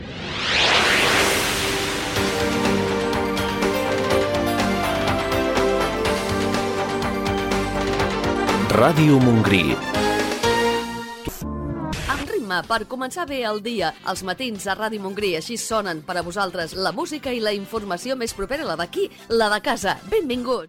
Indicatiu i benvinguda al programa.
Entreteniment
FM